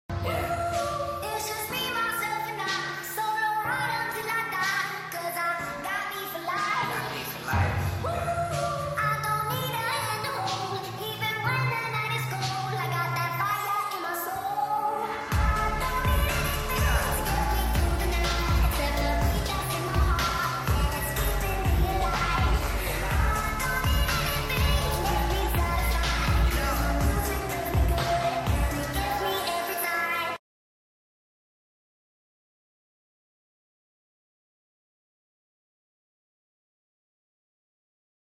🎧 16D - WEAR HEADPHONES 🎧